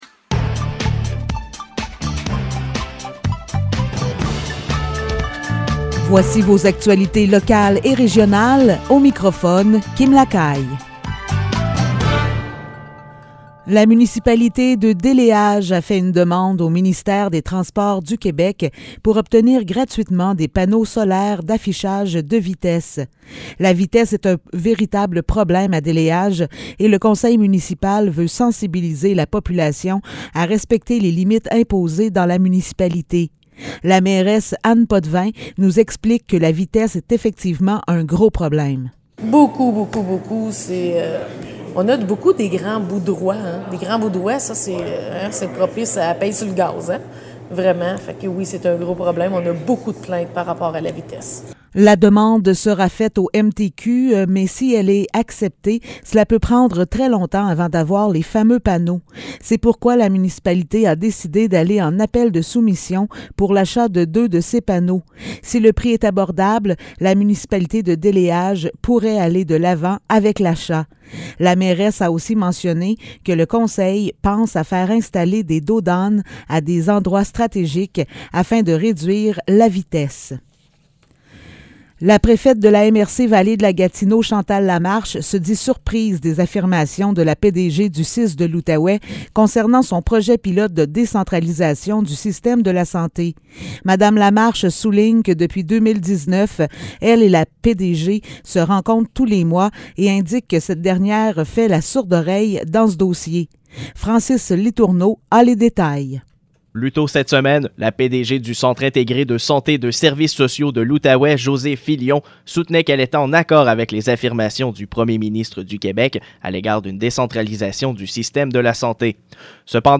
Nouvelles locales - 3 mars 2022 - 15 h